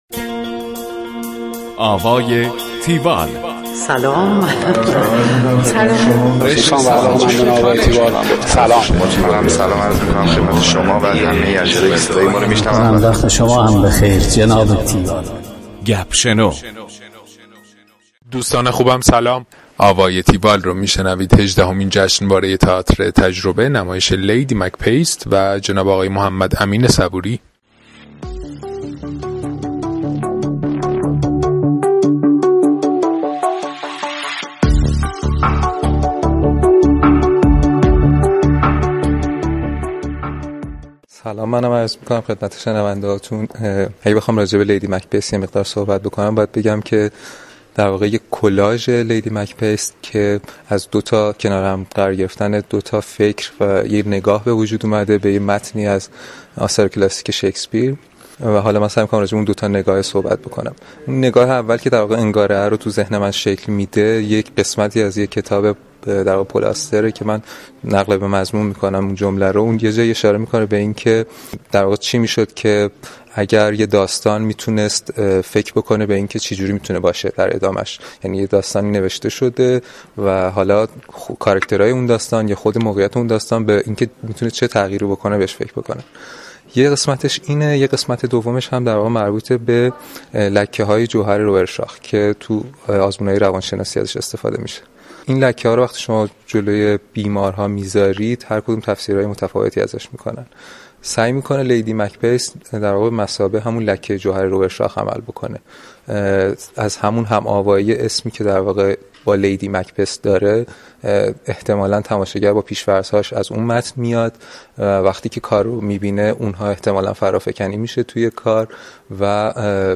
گفتگو کننده